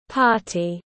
En effet, le son i anglais, ressemble davantage au son é (e accent aigu) qu’au i français.
L’enregistrement suivant vous permet d’en avoir la prononciation exacte :
party.mp3